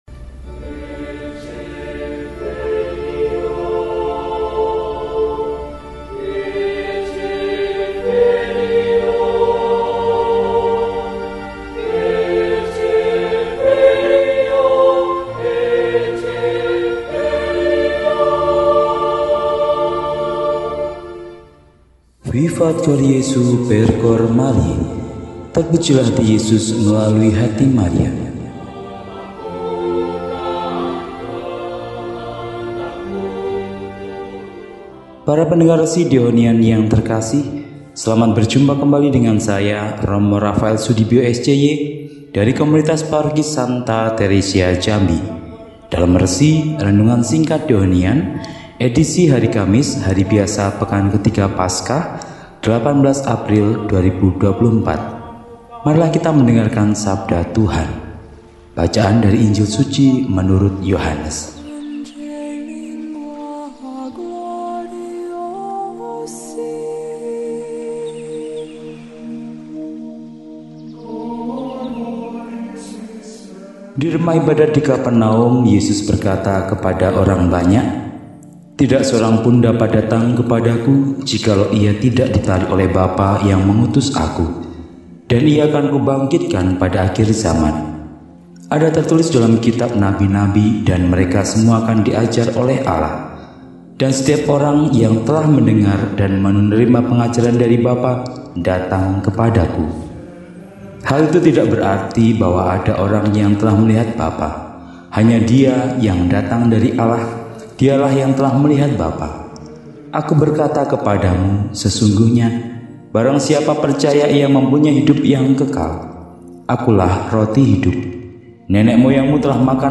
Kamis, 18 April 2024 – Hari Biasa Pekan III Paskah – RESI (Renungan Singkat) DEHONIAN